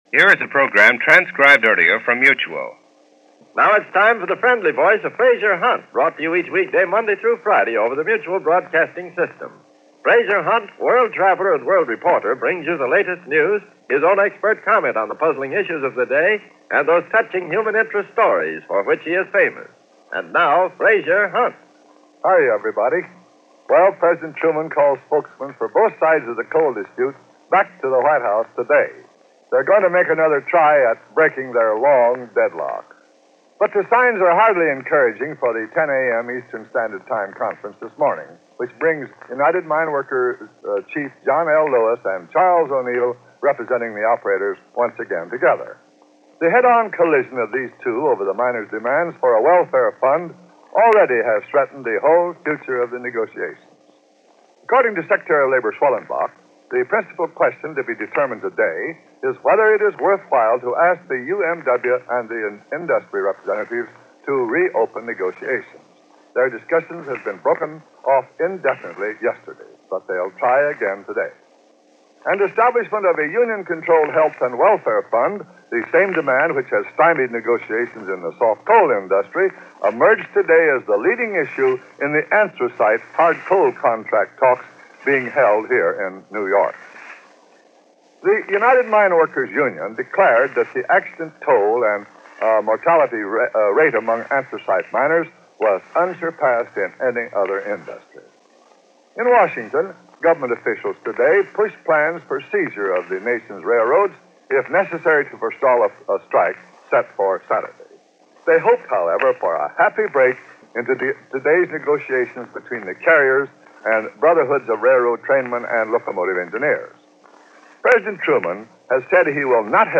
May 16, 1946 – MBS: Frazier Hunt News And Commentary – Gordon Skene Sound Collection –